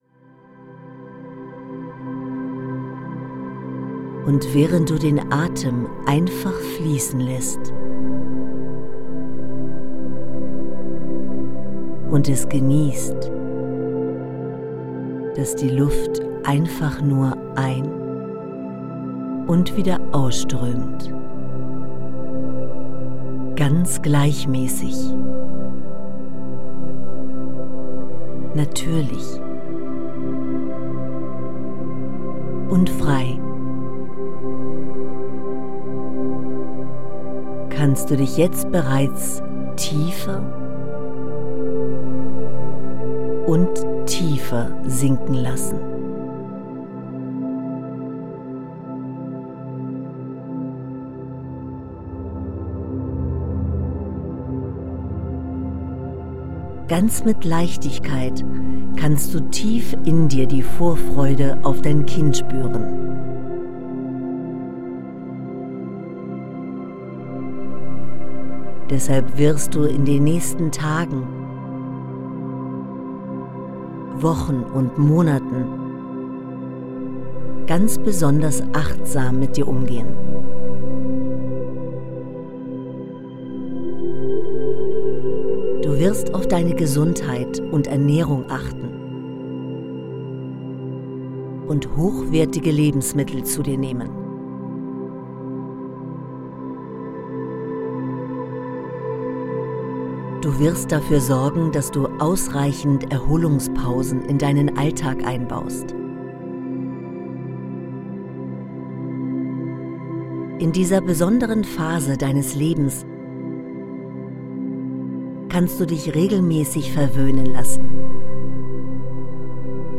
Dieses Mindstyle Audiobook ist ein harmonisches Zusammenspiel von inspirierenden Texten, bewegenden Stimmen und sanfter Begleitmusik.